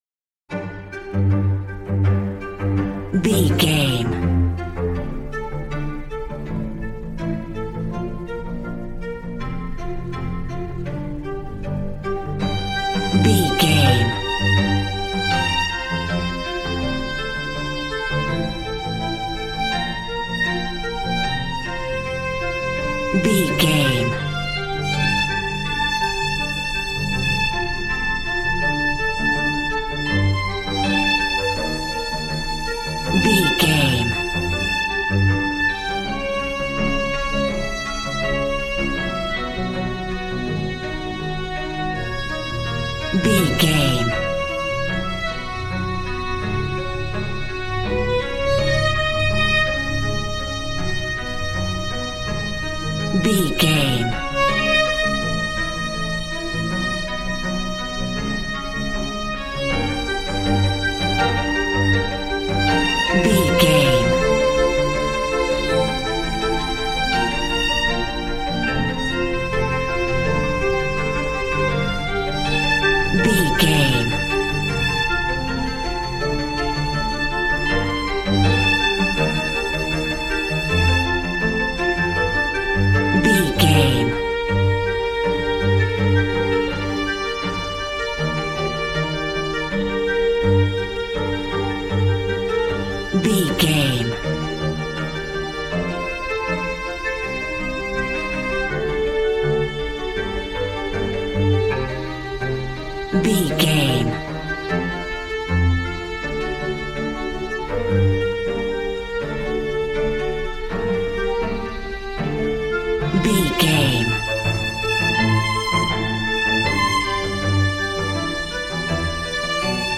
Aeolian/Minor
regal
brass